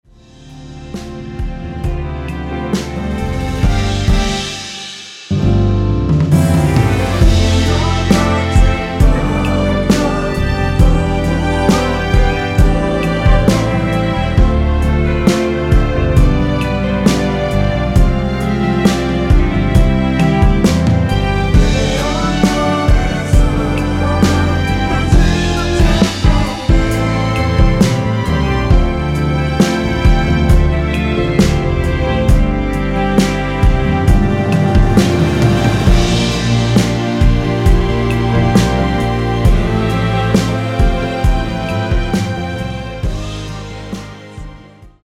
원키에서(-3)내린 코러스 포함된 MR입니다.
앞부분30초, 뒷부분30초씩 편집해서 올려 드리고 있습니다.
중간에 음이 끈어지고 다시 나오는 이유는